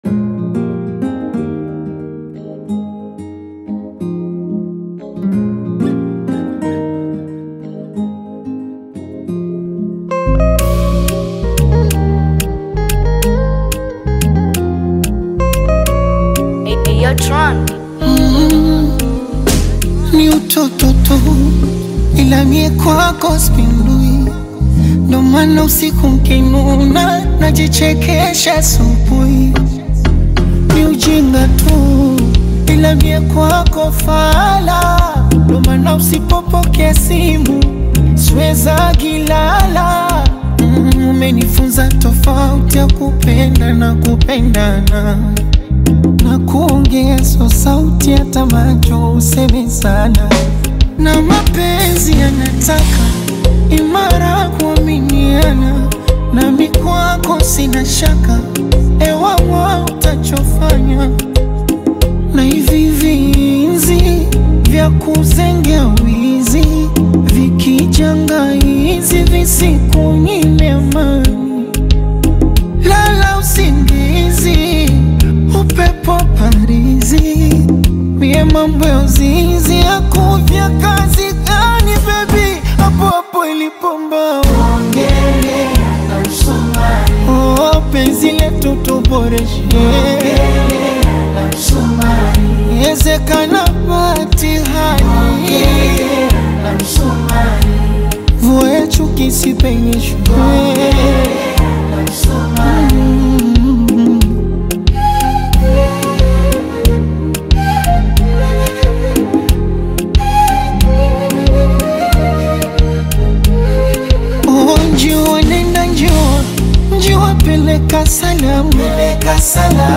Africa’s undisputed king of Bongo Flava